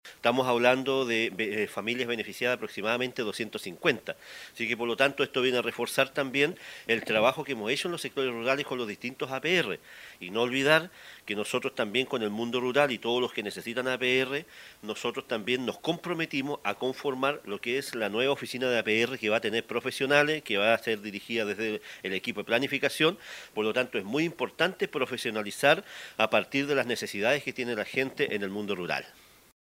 La inversión, que bordea los $30 millones de pesos, viene a responder a las necesidades de suministro para ese sector de la comuna.  El alcalde Gervoy Paredes se refirió a la aprobación de recursos para este proyecto, enfatizando en que ello viene a reforzar también el trabajo realizado en sectores rurales con distintos APR.